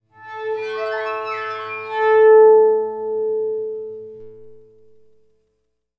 Source: Resonated harmonic gliss on A (6:00-7:28)
Res_Harmonic_A.aiff